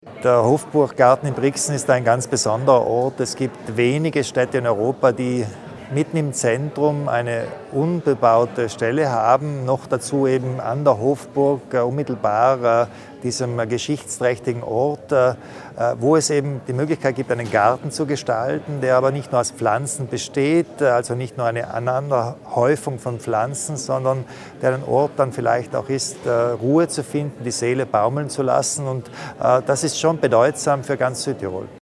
Landeshauptmann Kompatscher zur Bedeutung des Projekts von André Heller